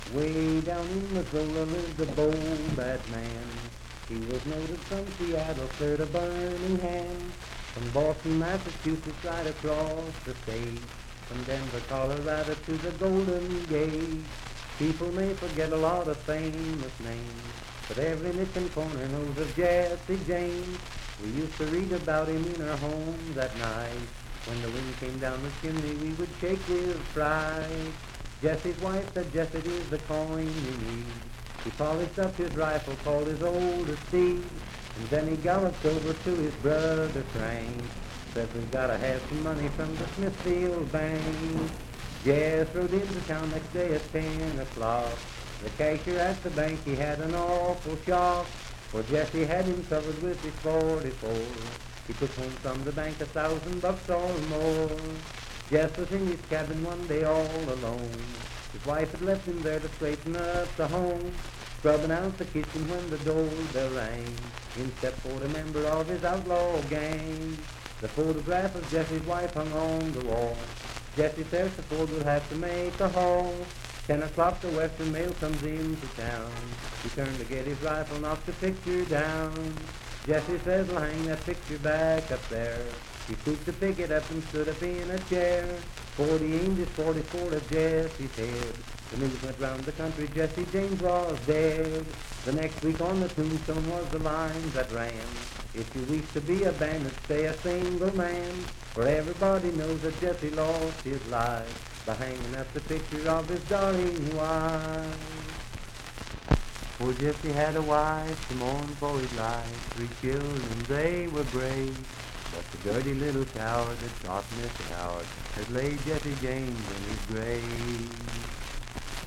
Unaccompanied vocal music
Verse-refrain 7(4).
Voice (sung)
Parkersburg (W. Va.), Wood County (W. Va.)